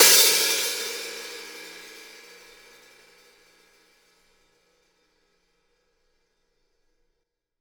West MetroHihat (2).wav